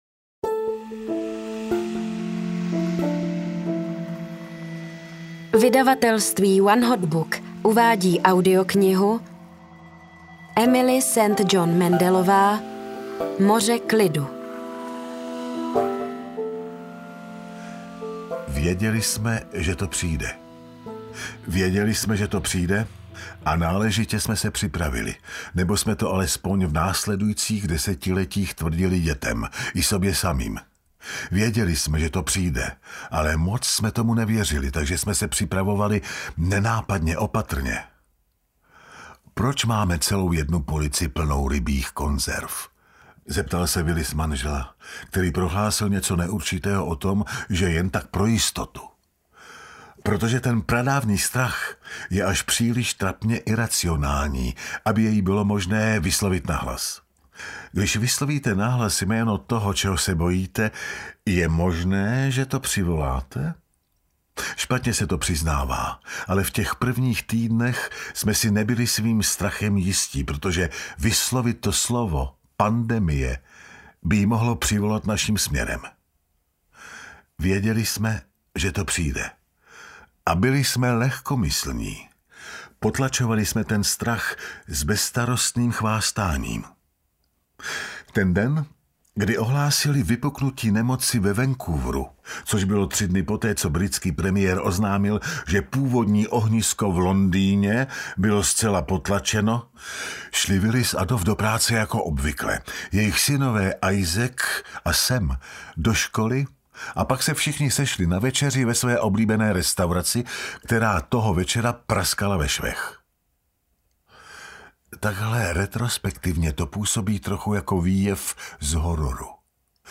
Moře klidu audiokniha
Ukázka z knihy